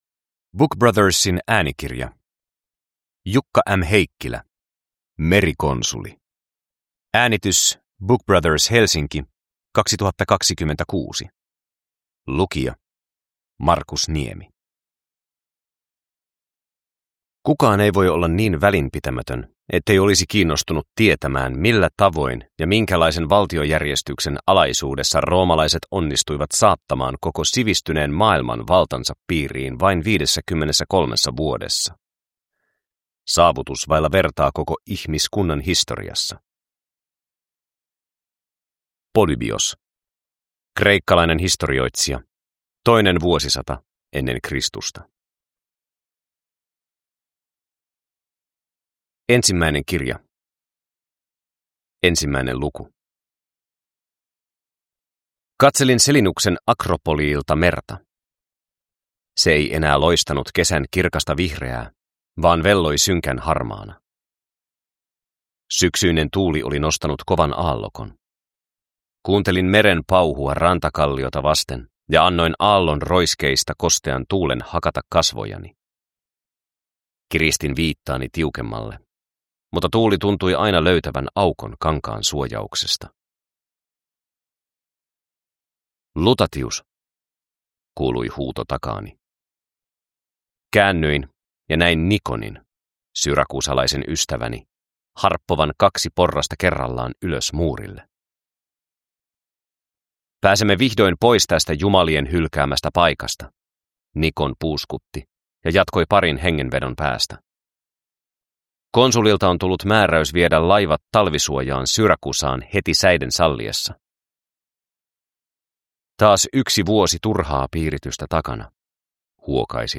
Merikonsuli – Ljudbok
Merikonsuli on historiallinen romaani, joka kuvaa minäkertojan äänellä Rooman ja Karthagon välistä merisotaa.